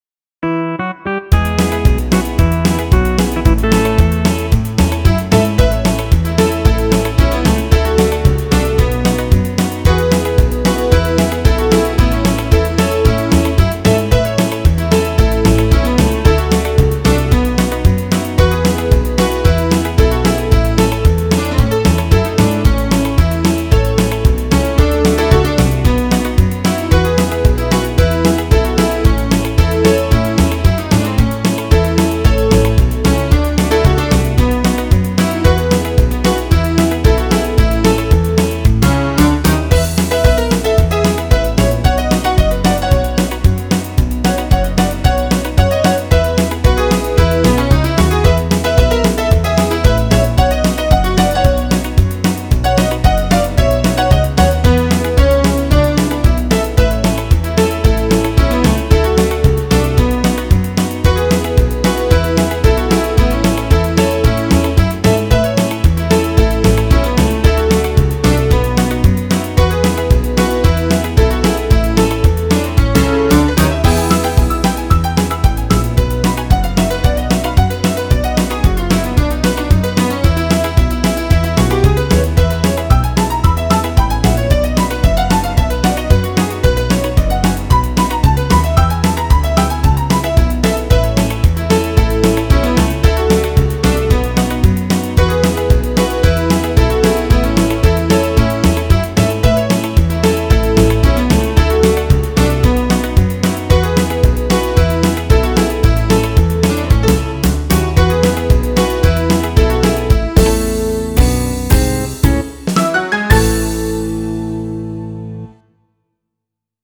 Do you need a toe-tapper?
This classic country tune would be the one.